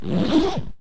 unzips.mp3